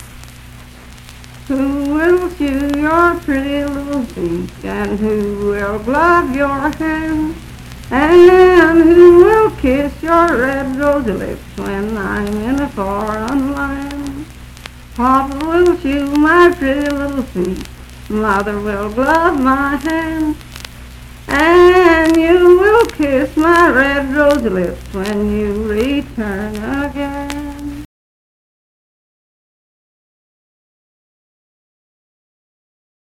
Unaccompanied vocal music
Verse-refrain 2(4).
Voice (sung)
Roane County (W. Va.), Spencer (W. Va.)